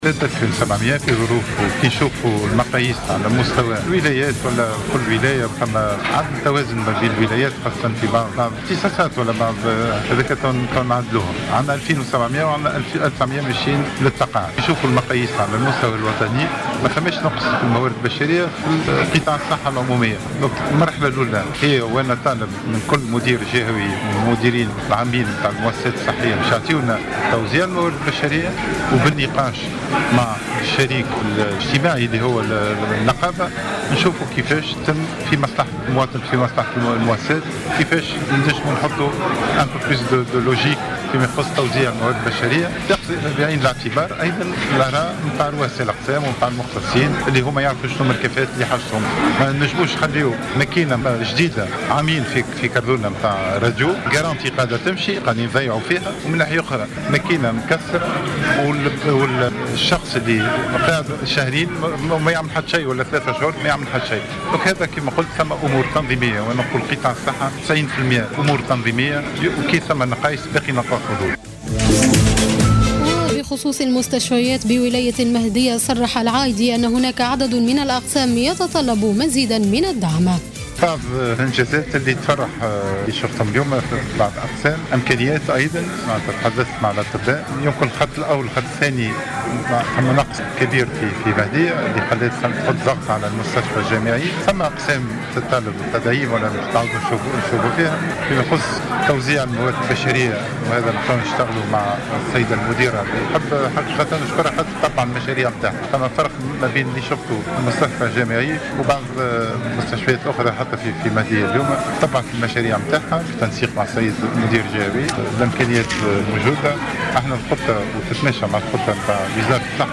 وأفاد في تصريحات لـ "الجوهرة أف أم" أن عدد الانتدابات في قطاع الصحة بالنسبة لسنة 2016 سيكون في حدود 2700 وظيفة وأن 1900 سيُحالون على التقاعد.